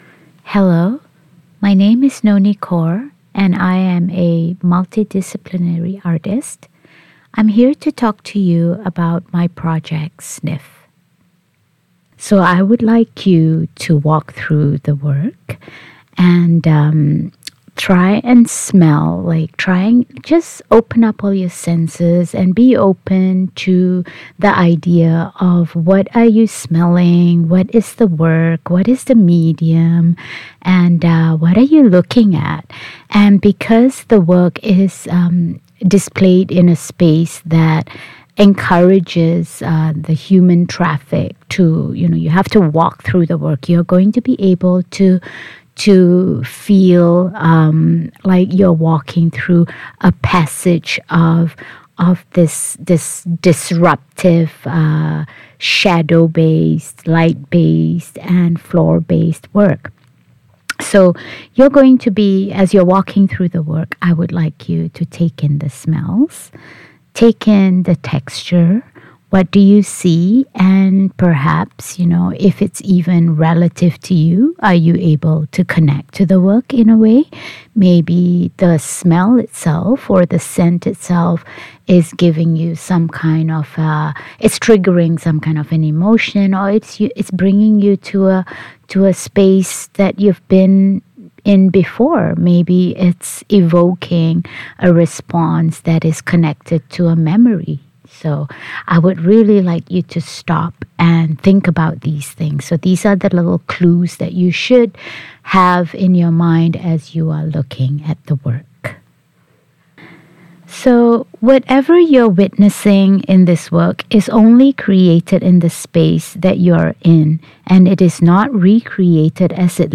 self-guided audio tour